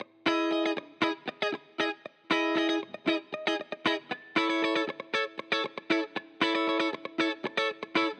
04 Guitar PT1.wav